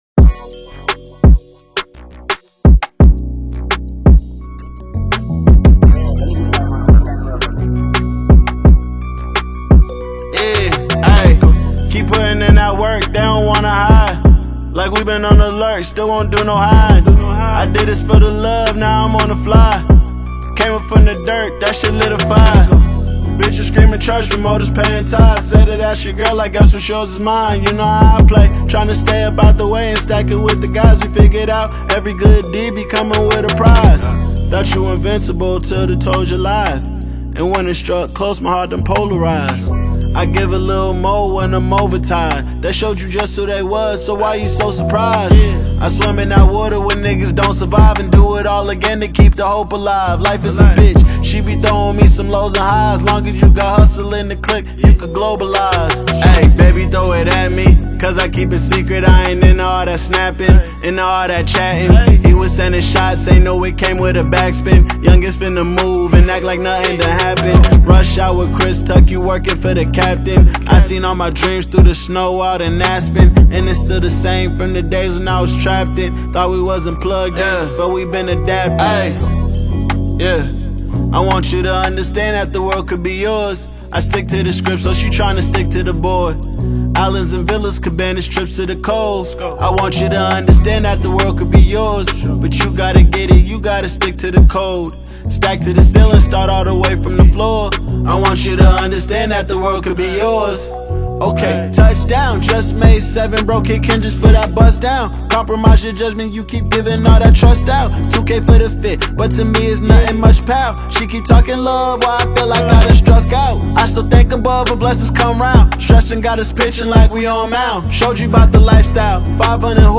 Super talented American rapper and songwriter